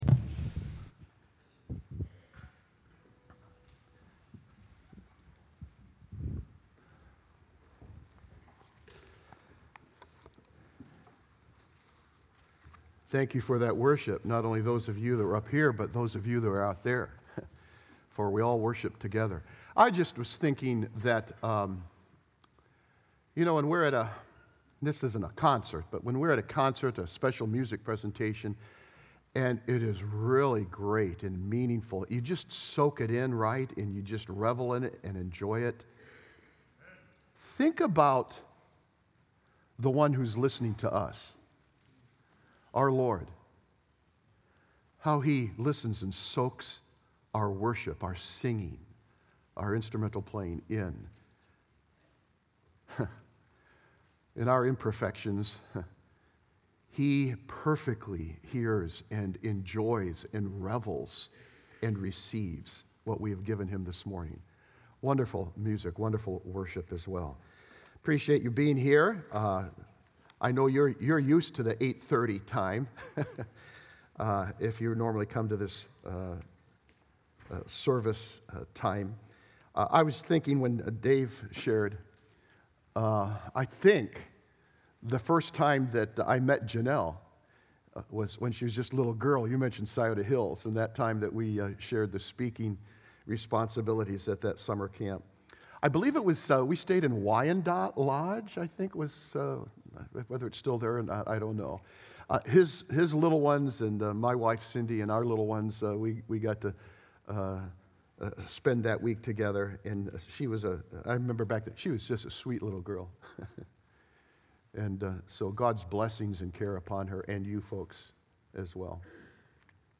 Much Received, Much Required | Baptist Church in Jamestown, Ohio, dedicated to a spirit of unity, prayer, and spiritual growth